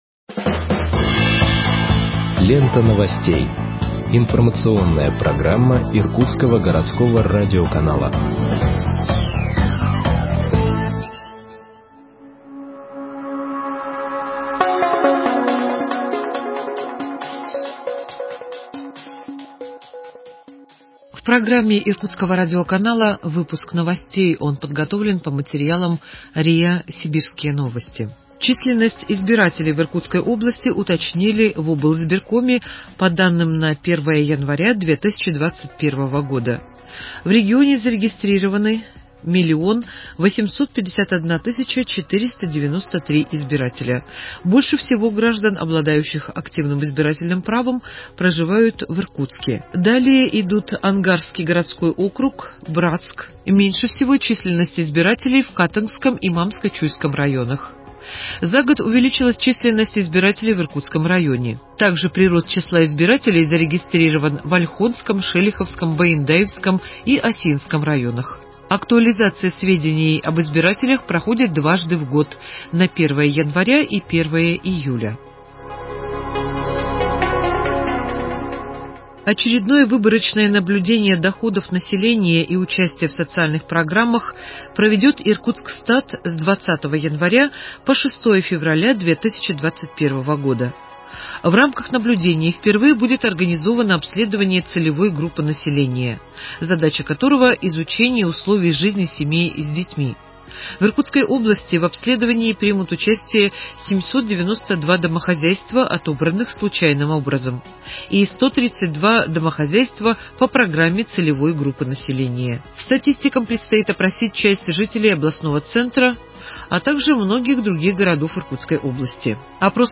Выпуск новостей в подкастах газеты Иркутск от 21.01.2021 № 1